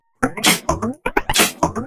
Index of /VEE/VEE2 Loops 128BPM
VEE2 Electro Loop 290.wav